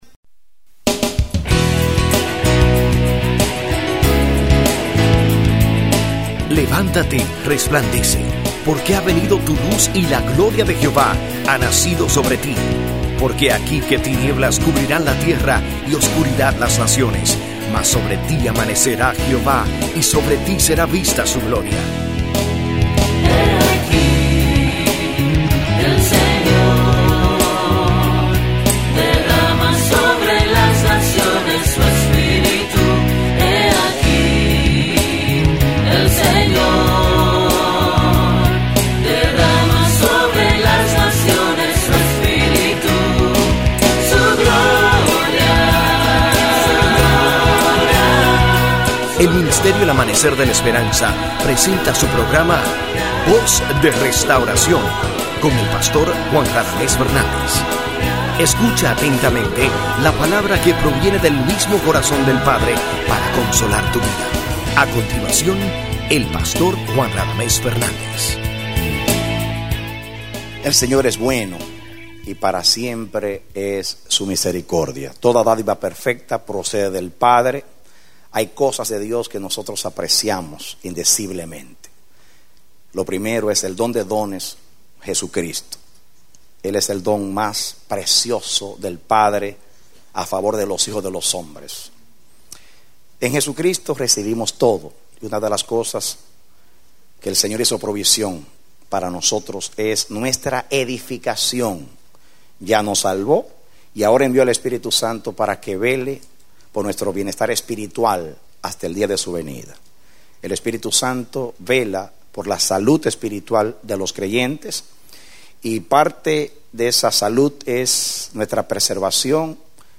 Predicado Octubre 10, 2005